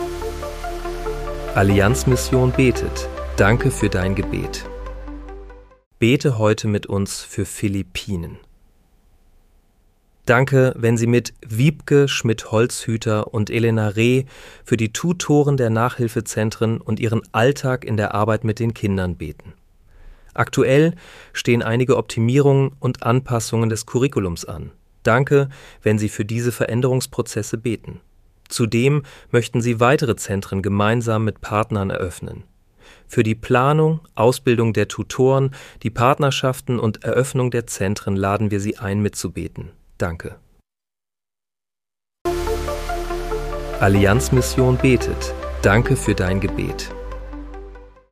Bete am 02. März 2026 mit uns für Philippinen. (KI-generiert mit